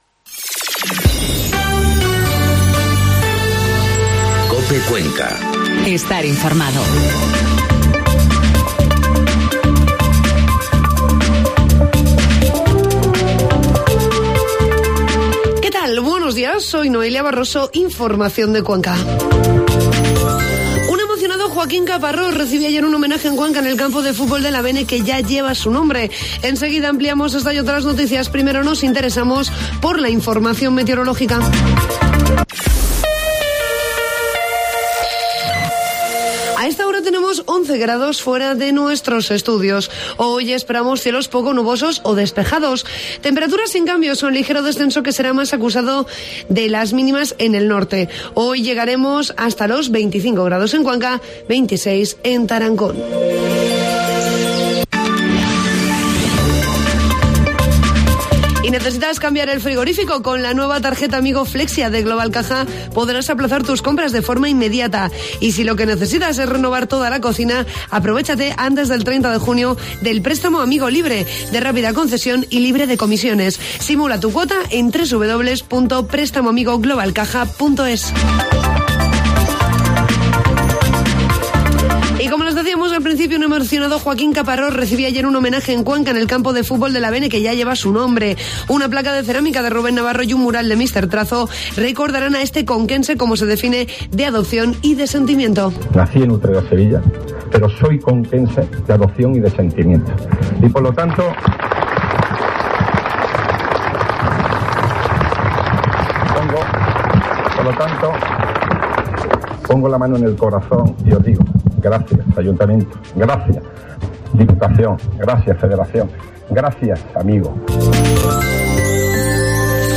Informativo matinal COPE Cuenca 10 de junio